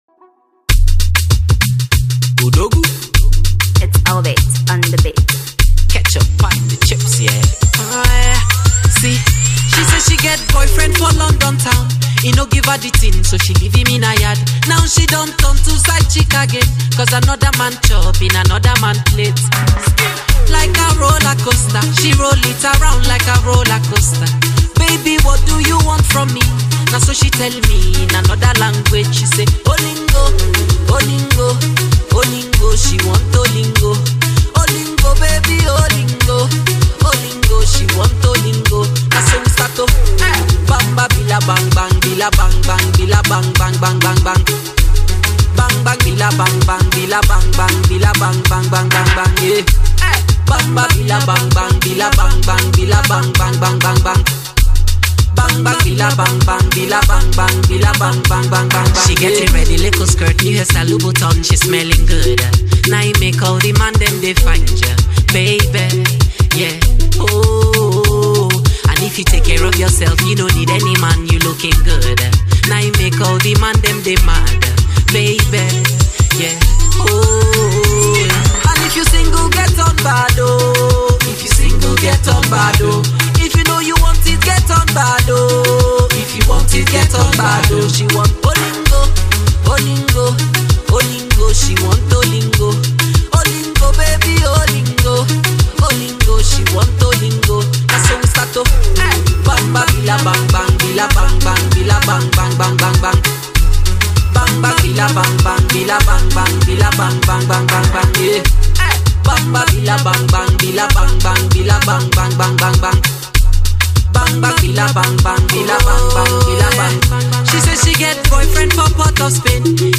Afro Dancehall